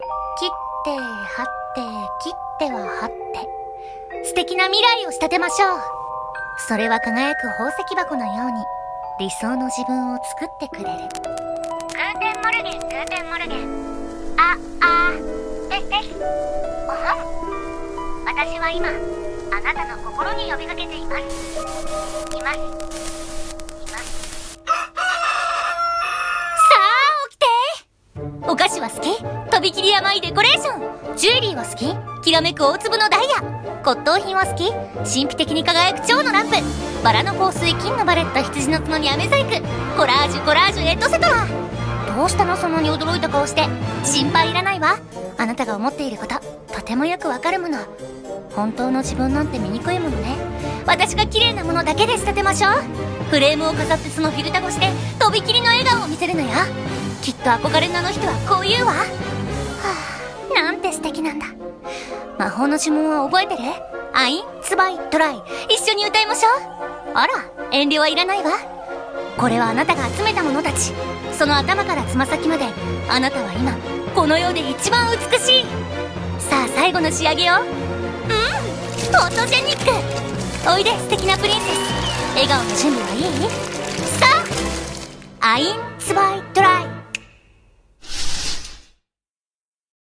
CM風声劇「クライン・レーヴェンの仕立師」